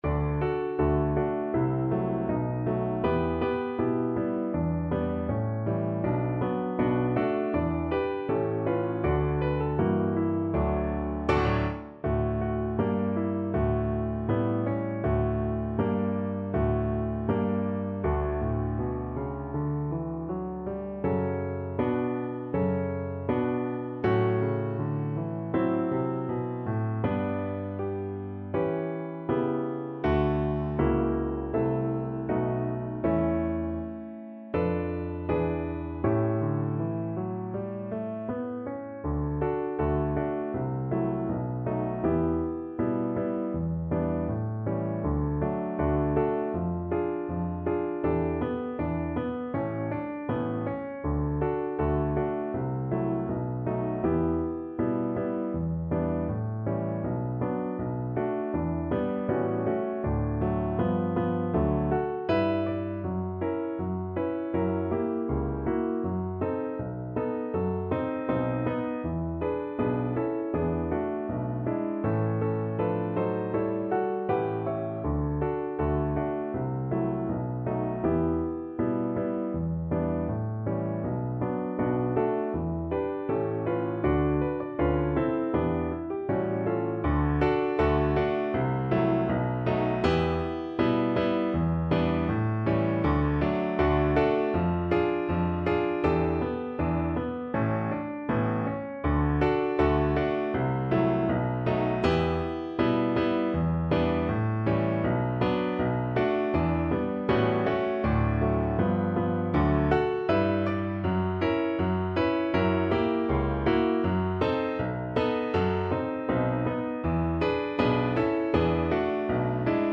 Db4-D5
~ = 100 Moderato =80
2/2 (View more 2/2 Music)
Pop (View more Pop Cello Music)
film (View more film Cello Music)